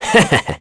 Esker-Vox-Laugh.wav